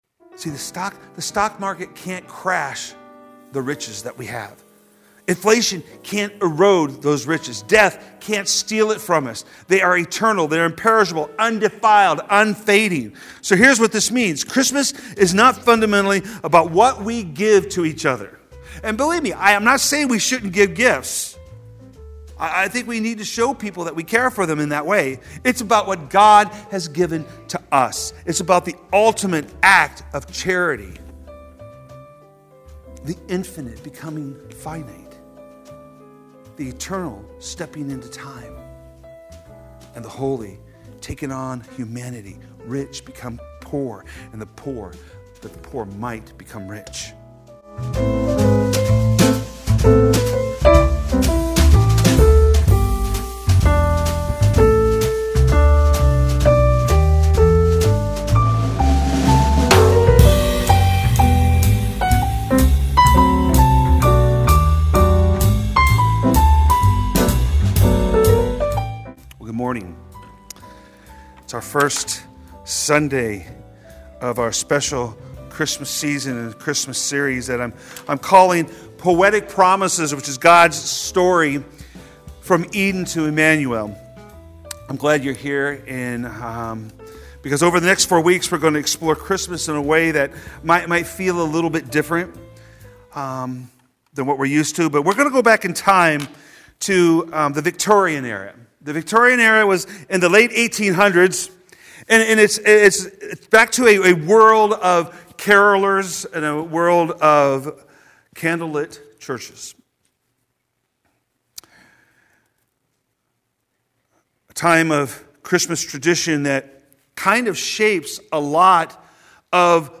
Sermon Main - Living Faith Missionary Church